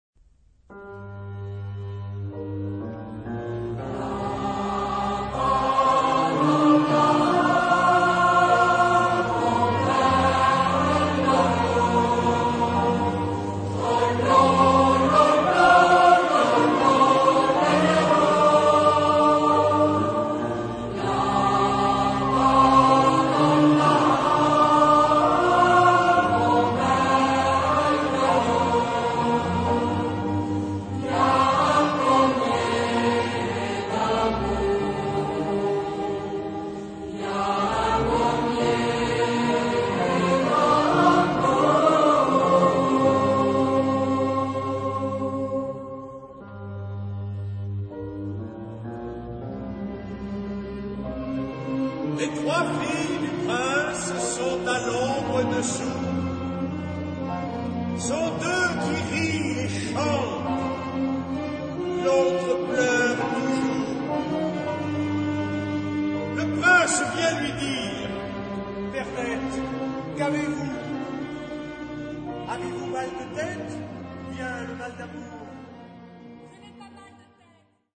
Genre-Style-Forme : Populaire ; Profane
Caractère de la pièce : ample
Type de choeur : SATB  (4 voix mixtes )
Instrumentation : Piano  (1 partie(s) instrumentale(s))
Tonalité : do mode de ré
Origine : Auvergne (France)